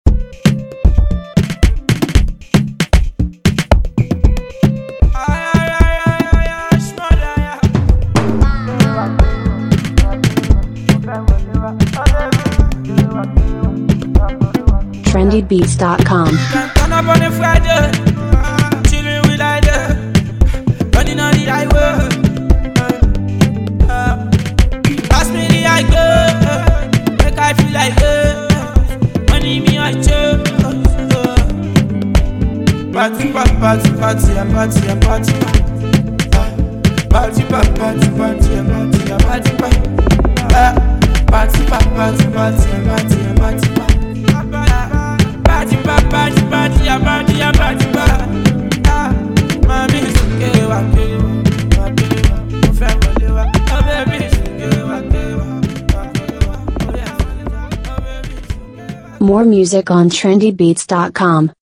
street hiphop
Check on the Amapiano influenced track and enjoy.